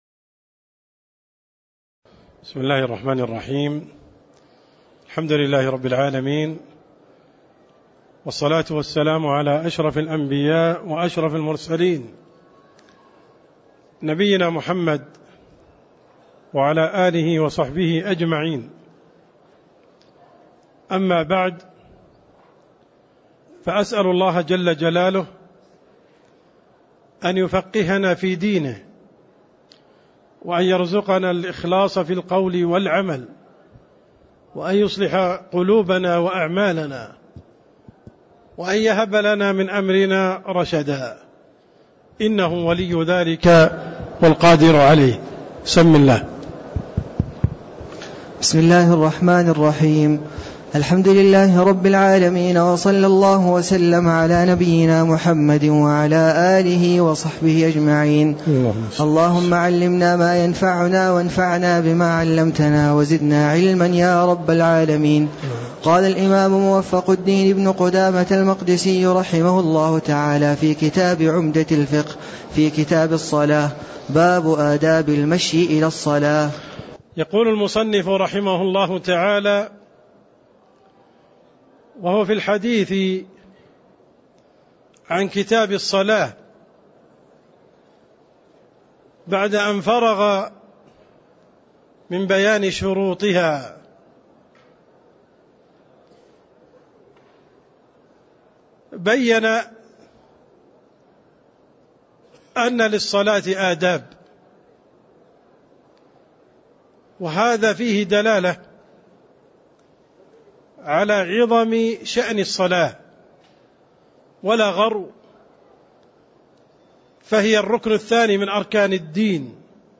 تاريخ النشر ١٨ محرم ١٤٣٦ هـ المكان: المسجد النبوي الشيخ: عبدالرحمن السند عبدالرحمن السند باب أدآب المشي إلى الصلاة (07)